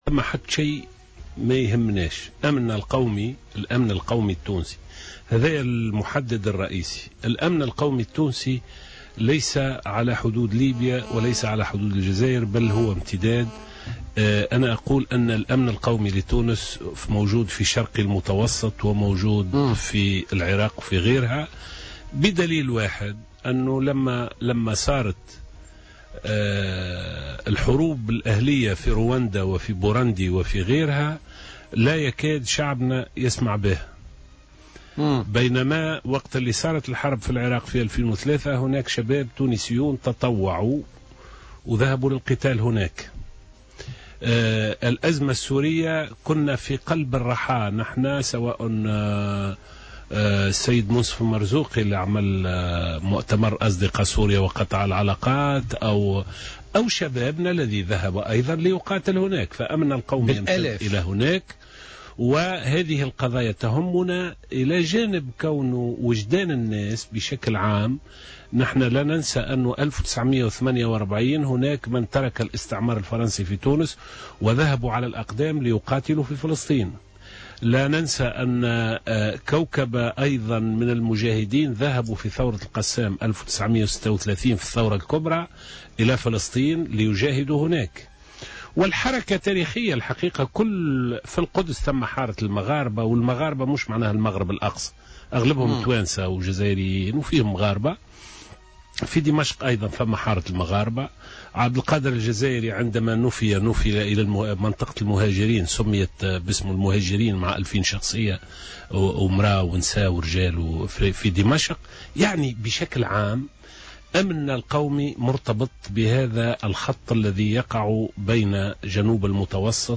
قال القيادي في حركة نداء تونس، لزهر العكرمي، ضيف برنامج "بوليتيكا" اليوم إن الأمن القومي التونسي ليس على حدود ليبيا والجزائر بل مرتبط أيضا بما يحدث في العراق وفلسطين و سوريا ولبنان.